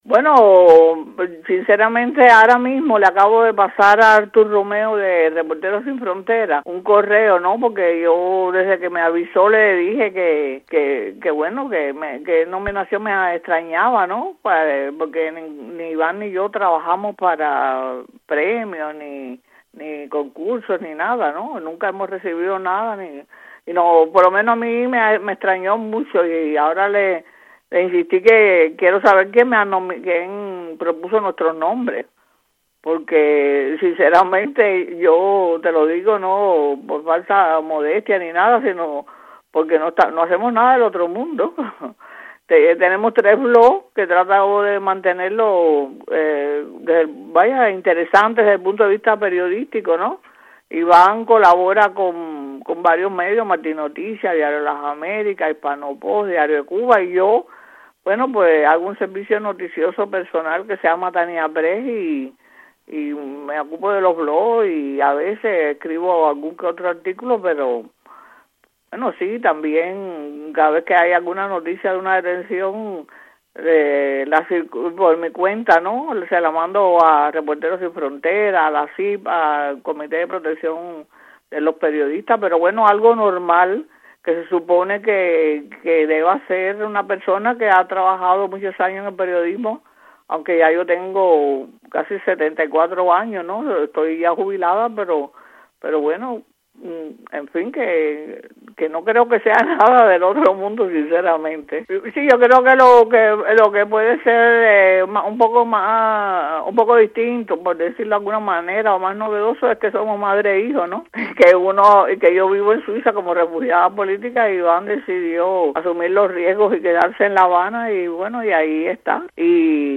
conversó con Martí Noticias sobre la nominación de RSF.